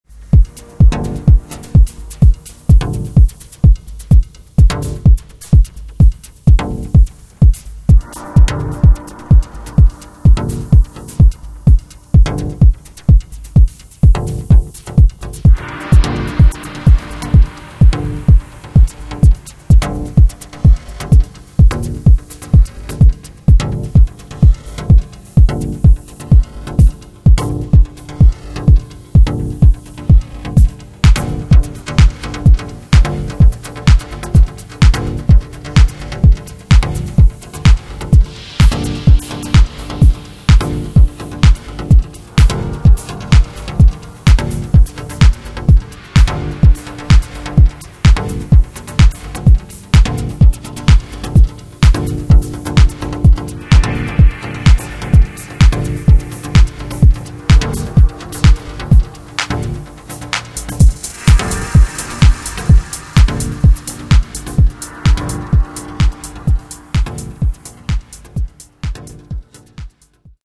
Dub Techno